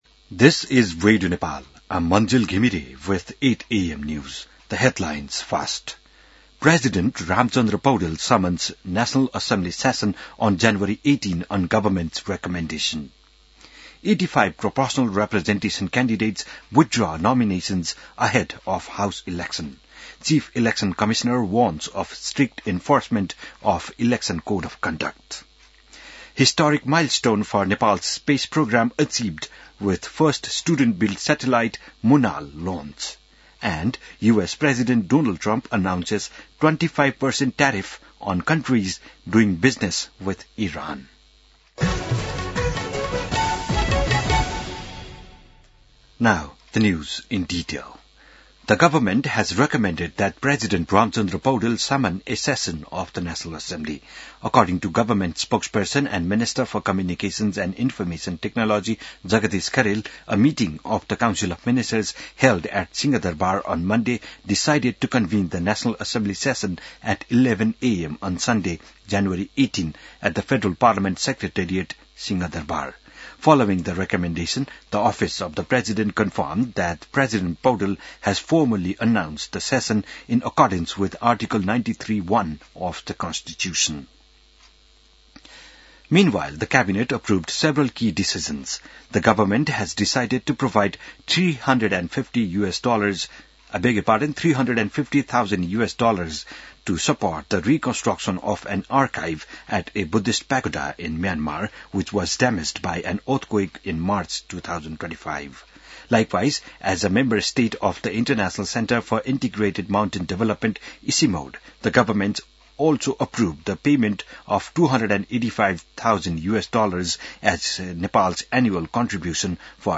बिहान ८ बजेको अङ्ग्रेजी समाचार : २९ पुष , २०८२